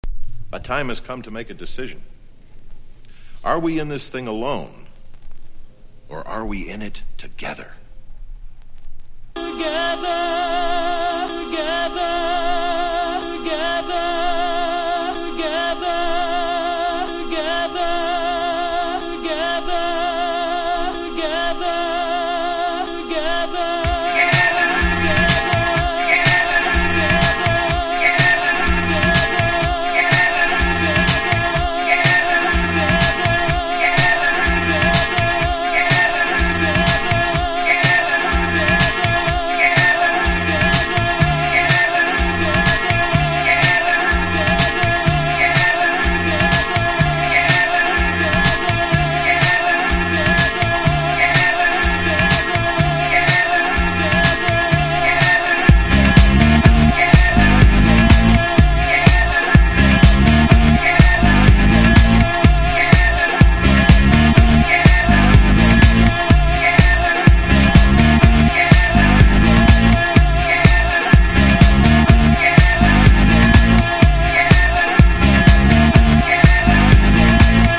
• HOUSE
# ELECTRO# ELECTRO HOUSE / TECH HOUSE# POP CLUB